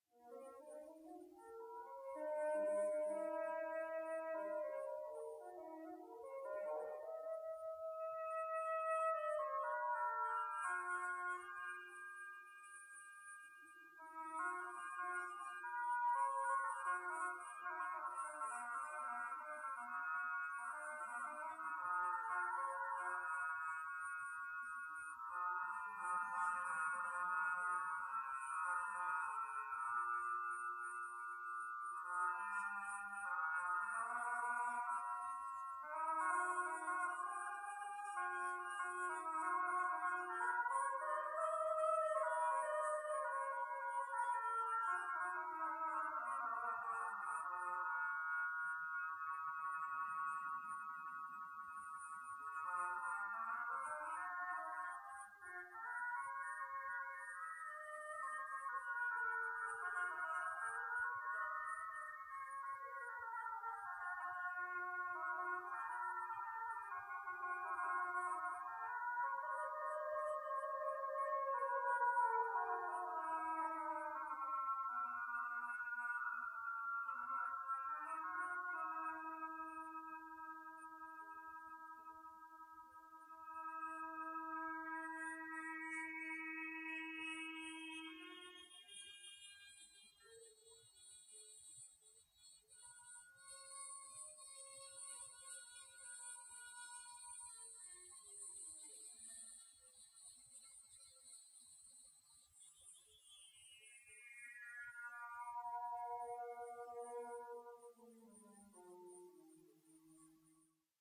The complete set of my modified Synthi A TKS